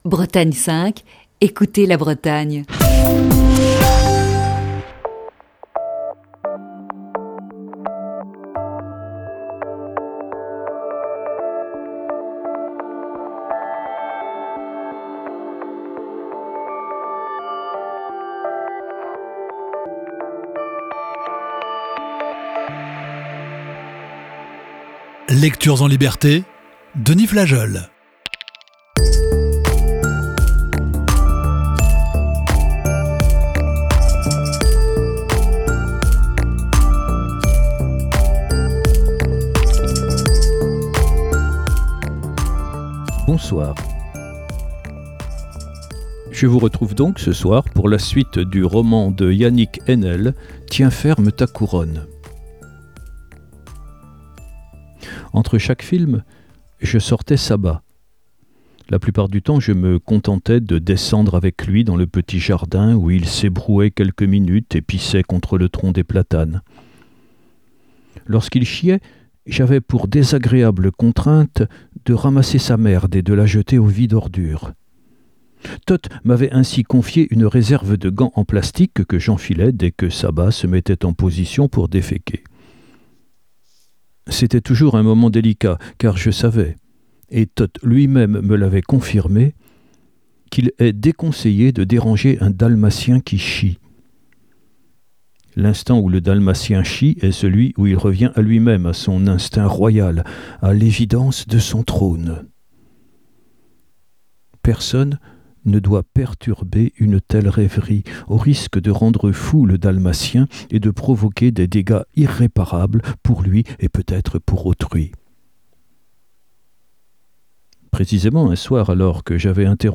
Émission du 12 novembre 2020.